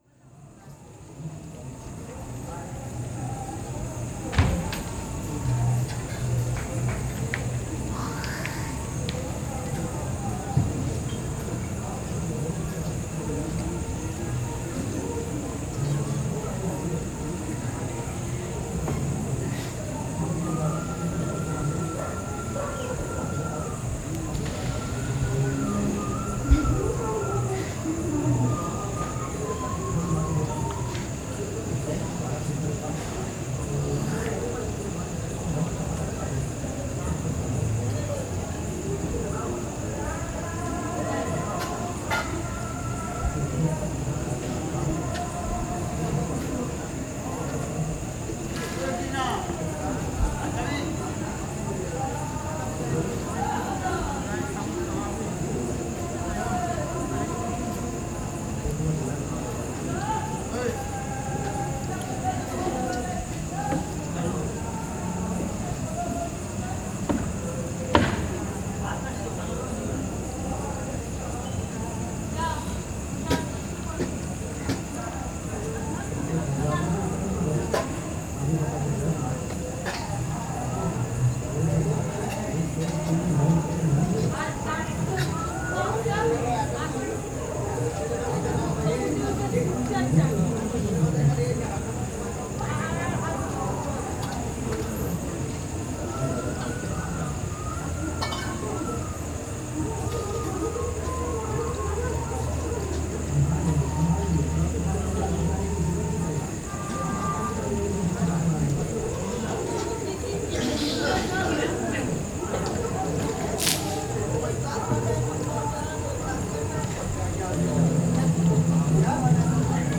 一位孤独的苦行僧深夜在Tolly运河洗澡，录于1998年6月
A lone Sadhu takes a late night bath in the Tolly Canal, recorded in June 1998